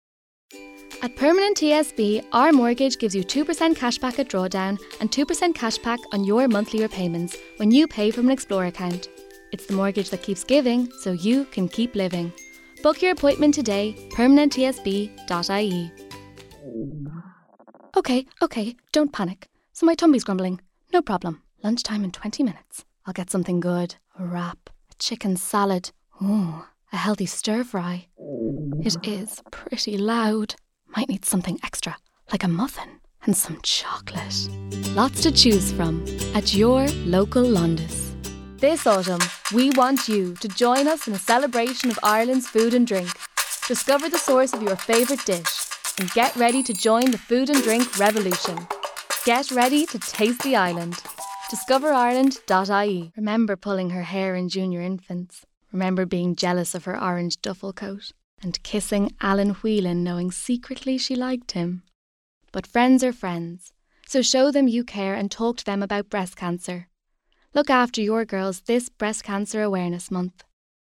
Female
20s/30s
Irish Dublin Neutral, Irish Neutral